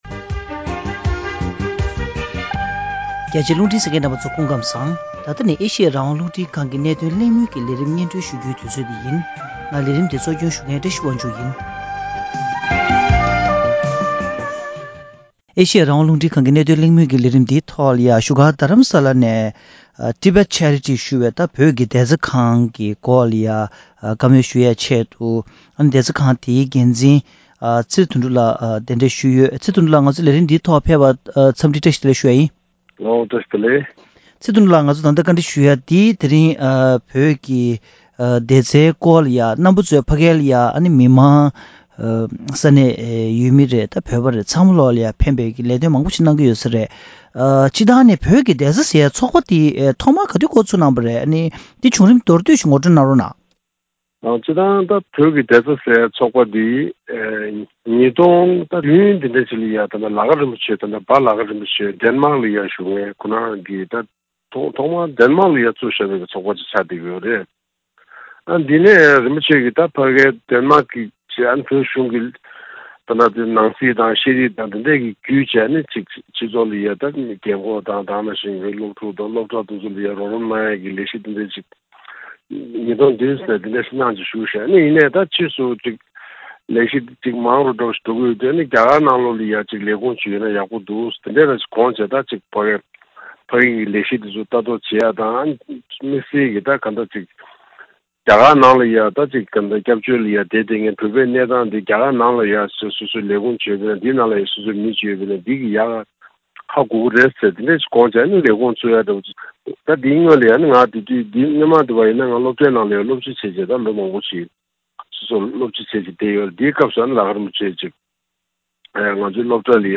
བོད་ཀྱི་བདེ་རྩ་ཚོགས་པའི་ལས་དོན་སྐོར་གླེང་མོལ།